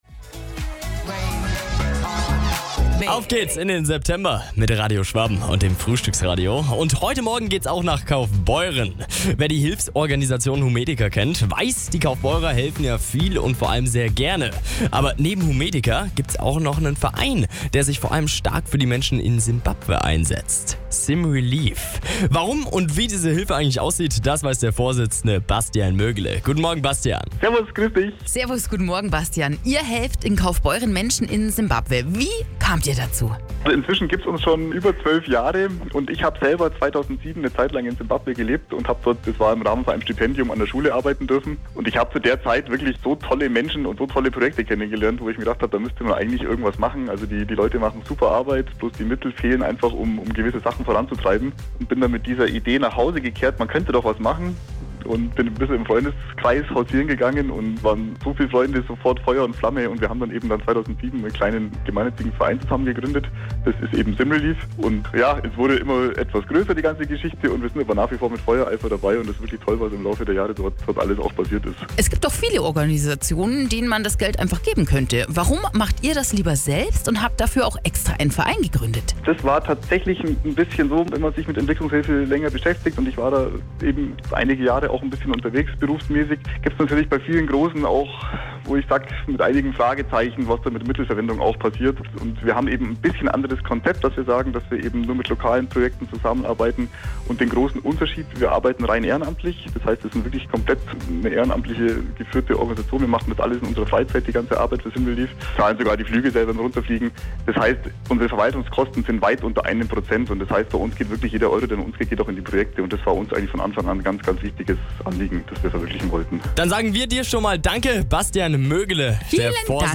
Beitrag Radio Schwaben (09.2020):